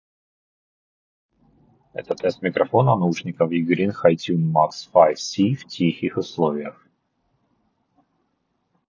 Микрофон
Запись голоса мне понравилась, по этому можно смело брать их и для долгих телефонных разговоров, вас точно услышат и разберут, что вы говорите, как на шумной улице, так и в тихом помещении.
В тихих условиях: